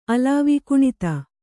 ♪ alāvi kuṇita